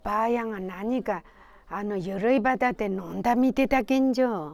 Aizu Dialect Database
Final intonation: Falling
Location: Aizumisatomachi/会津美里町
Sex: Female